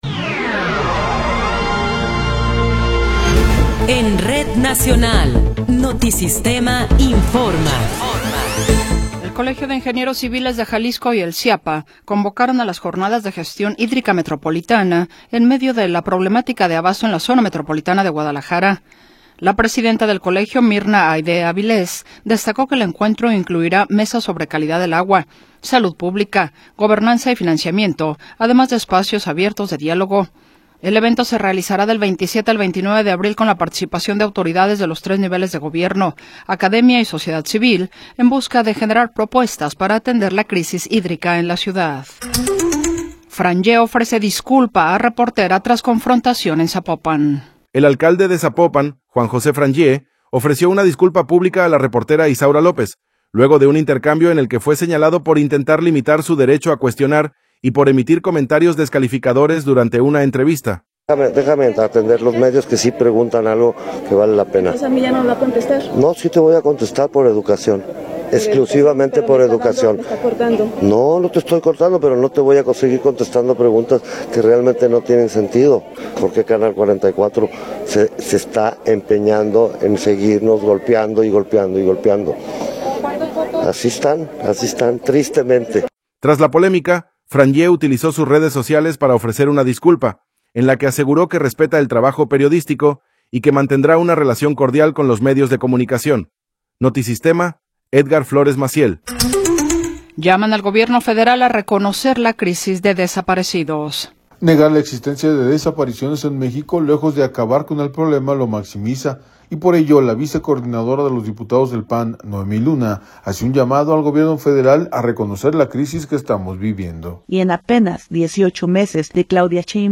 Noticiero 17 hrs. – 17 de Abril de 2026